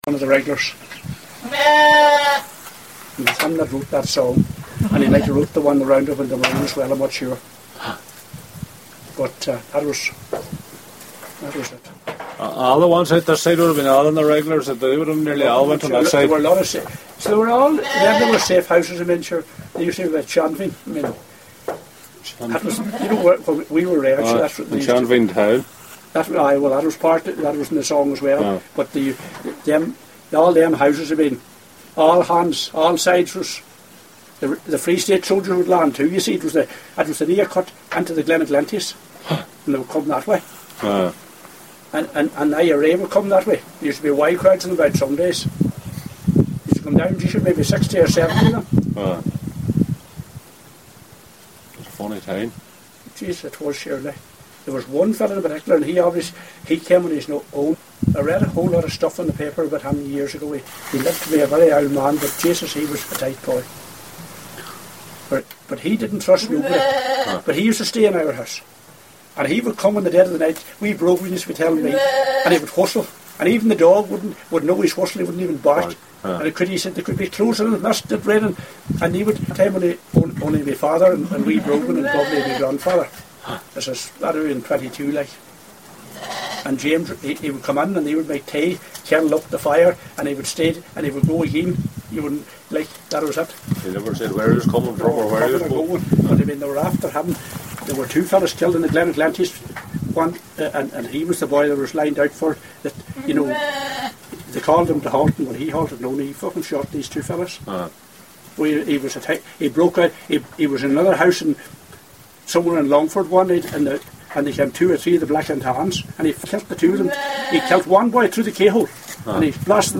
a local farmer